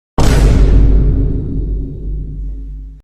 VINE_BOOM.mp3